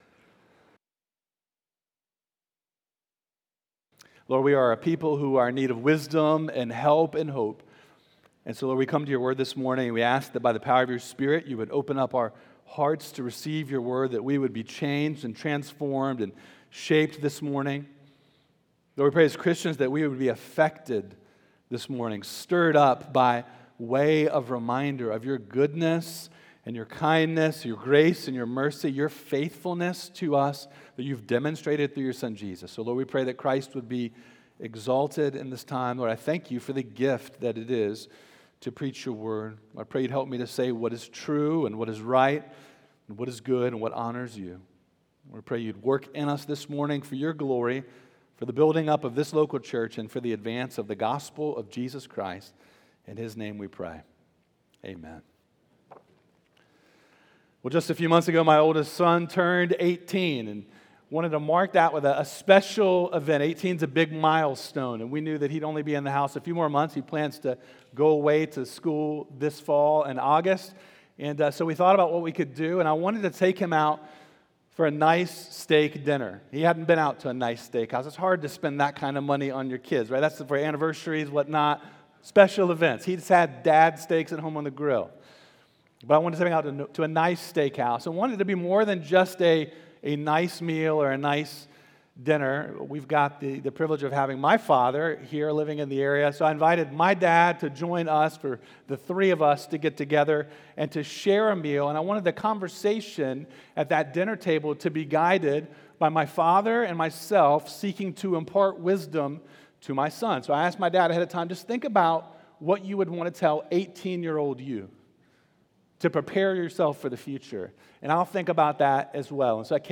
Sermons by Oakhurst Baptist Church Sermons